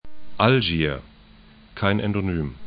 Algier 'alʒi:ɐ